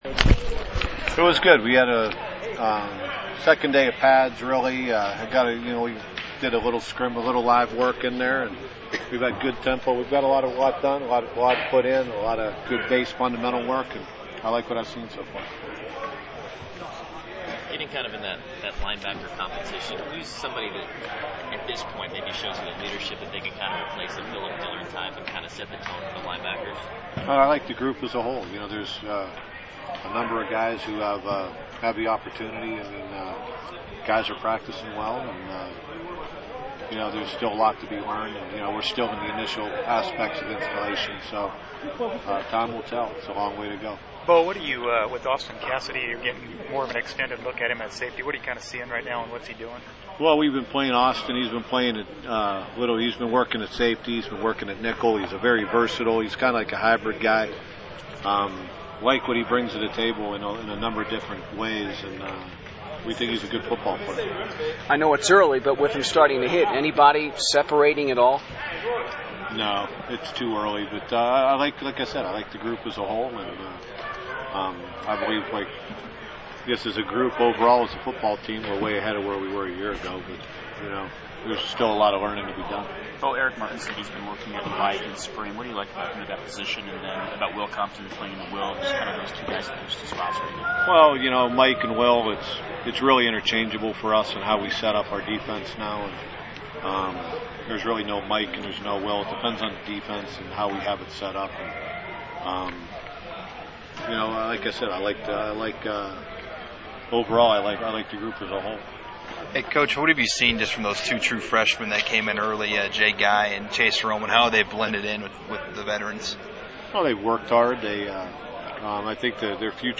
And if you want to know every little bit about that day's practice, you can always listen to the coaches' perspective in the full post-practice audio file.